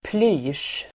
Ladda ner uttalet
Uttal: [ply:sj]